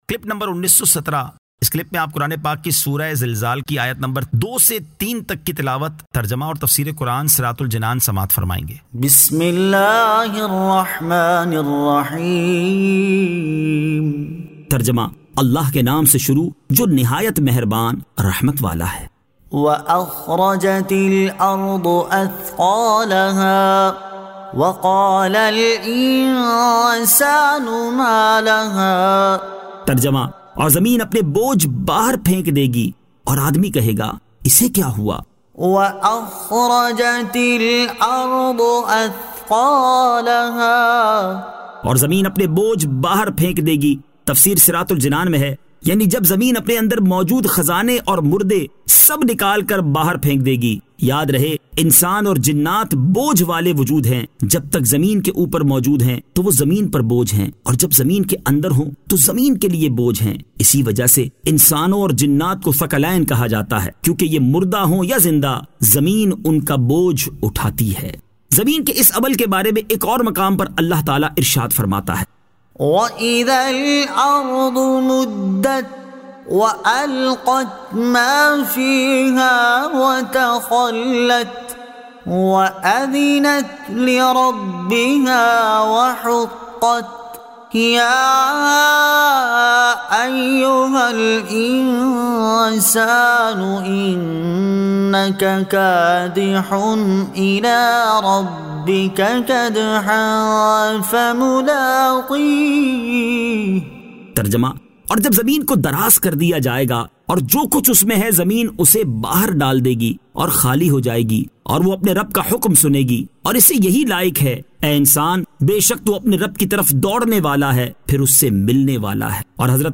Surah Al-Zilzal 02 To 03 Tilawat , Tarjama , Tafseer